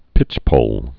(pĭchpōl)